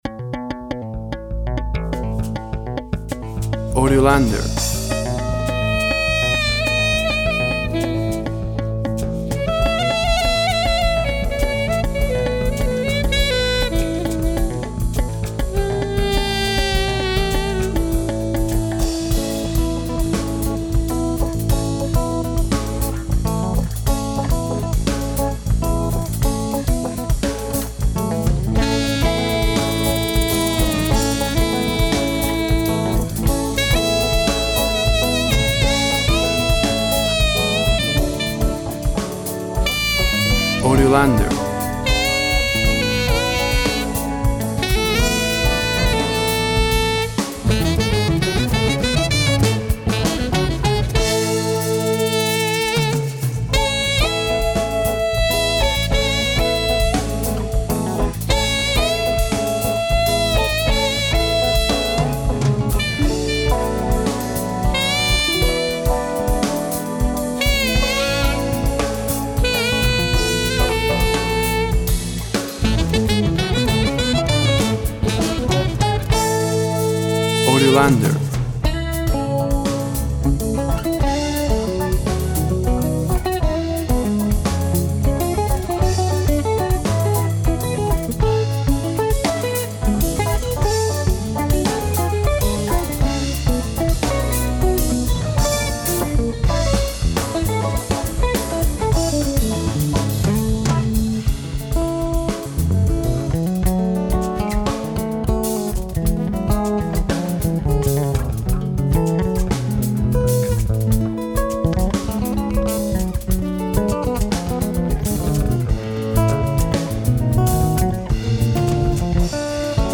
Jazz and Brazilian rhythms.